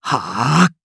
Siegfried-Vox_Casting1_jp.wav